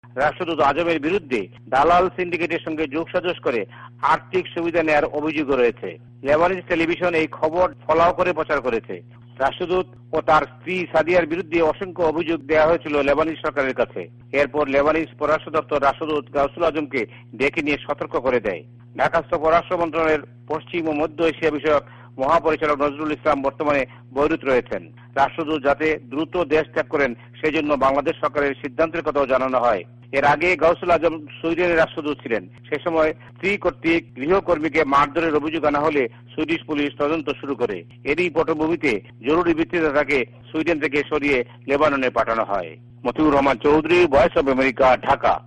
ভয়েস অফ এ্যামেরিকার ঢাকা সংবাদদাতাদের রিপোর্ট